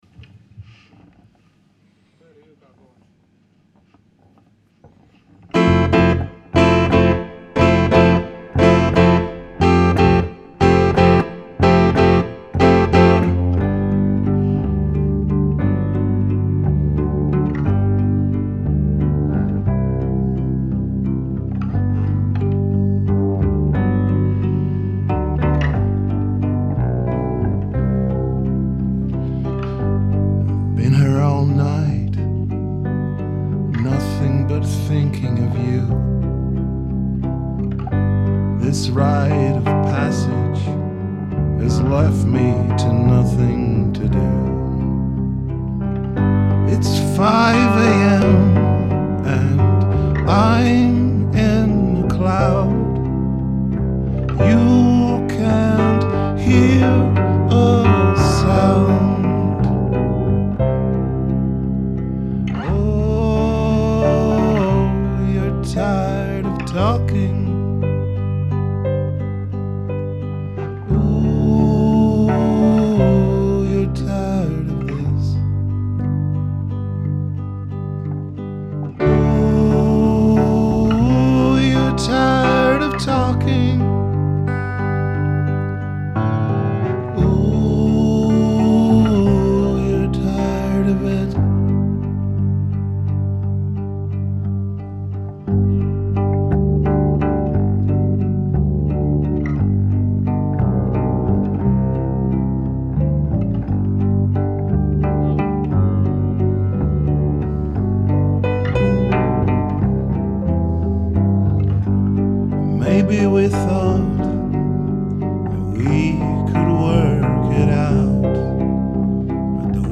Rehearsals 25.2.2012